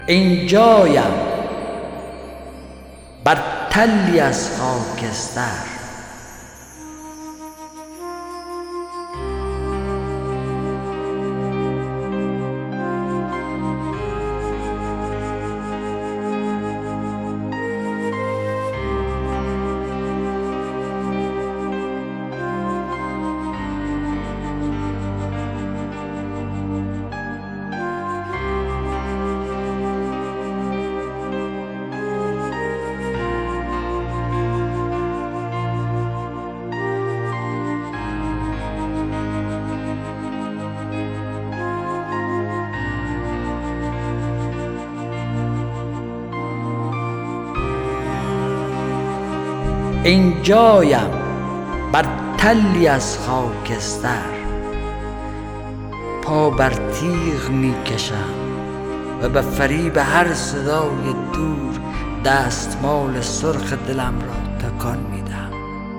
دانلود دکلمه دستمال سرخ دلم با صدای حسین پناهی
گوینده :   [حسین پناهی]